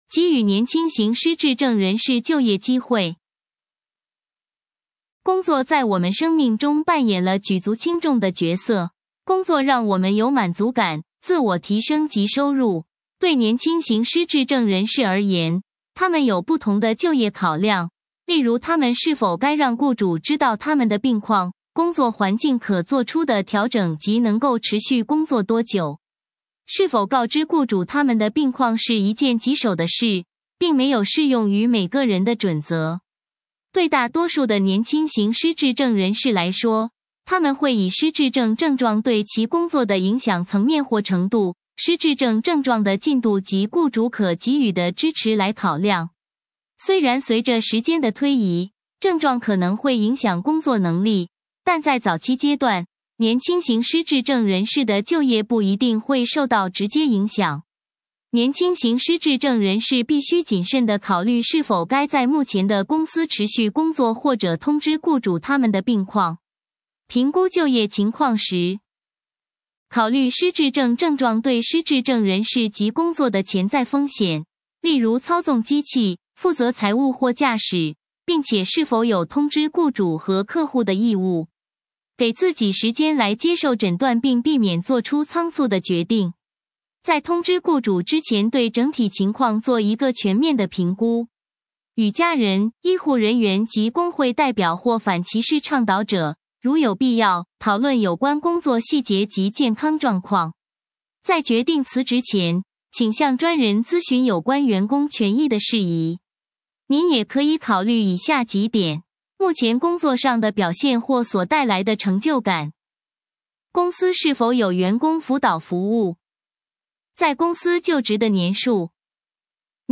文章朗读